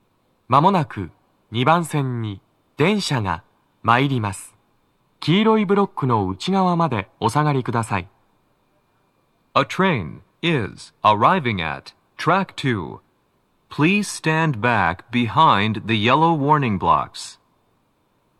2番線の鳴動は、やや遅めです。
男声
接近放送3